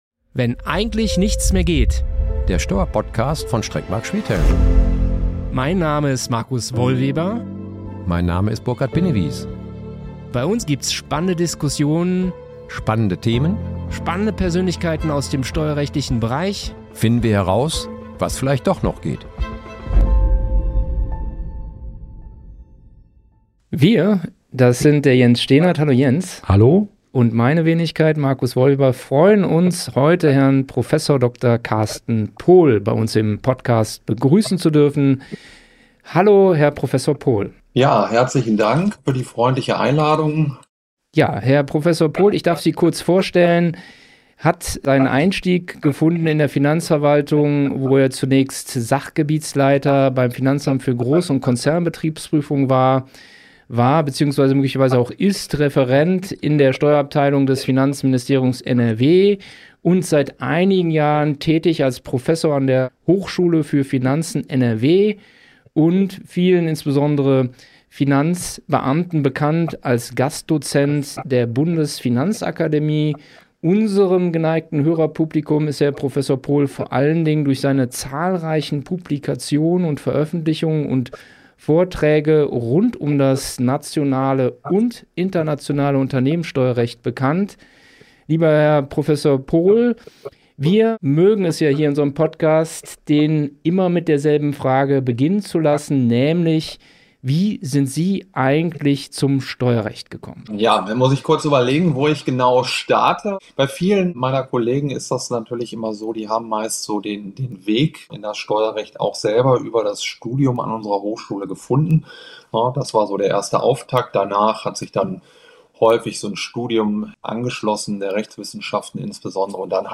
Der neue Umwandlungssteuererlass – Diskussion